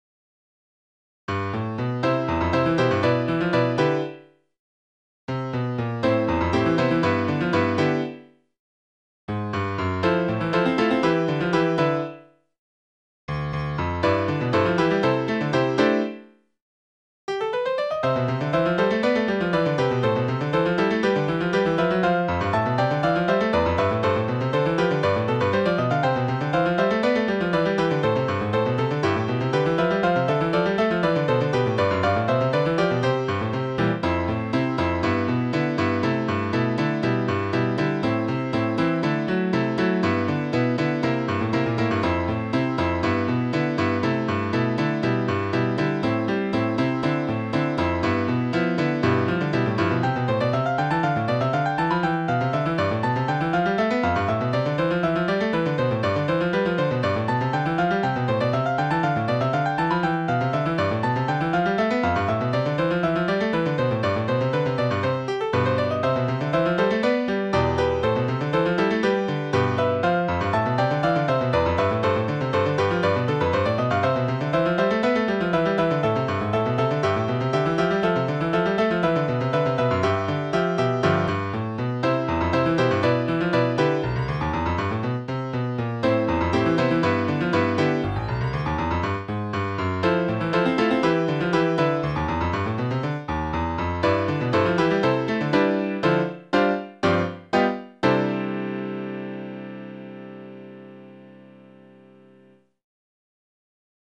ピアノソナタNo.2 in C dur
オリジナルのピアノソナタ2曲目。